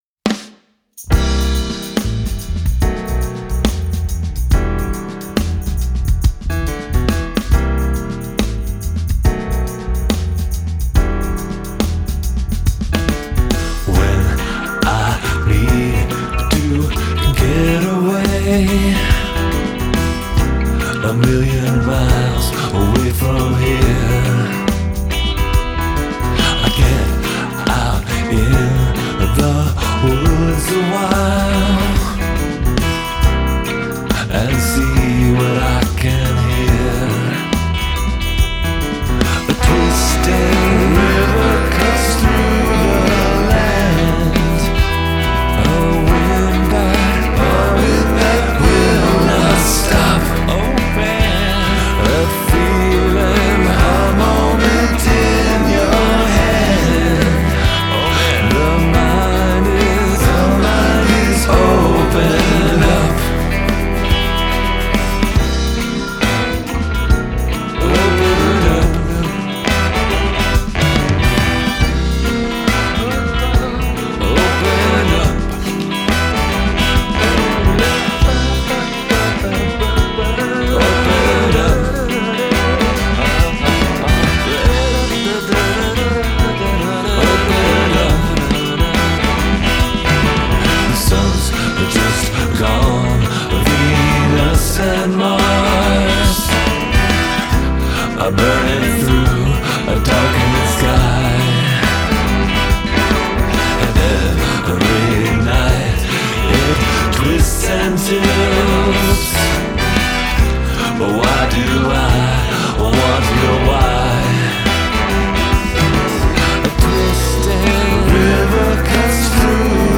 vocals, electric guitars, piano
drums, percussion
trumpet
trombone
alto saxophone
baritone saxophone
backing vocals